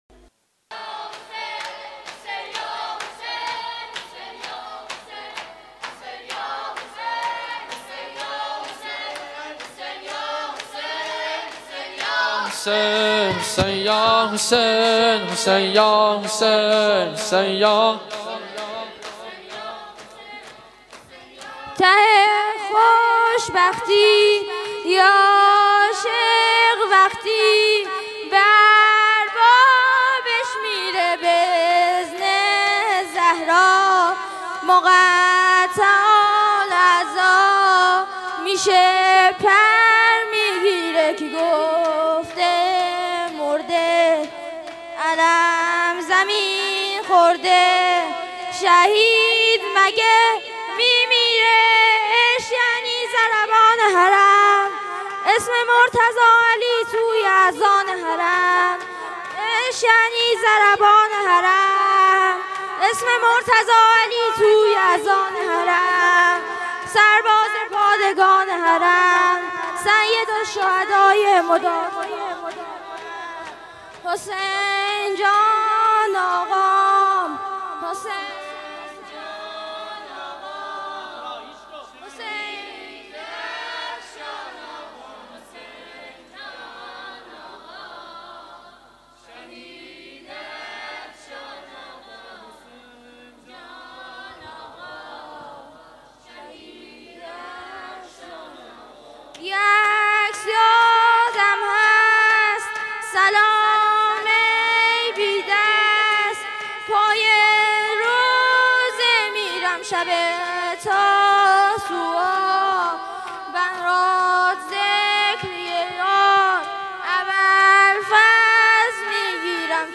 شهادت حضرت ام البنین1442